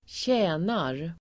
Uttal: [²tj'ä:nar]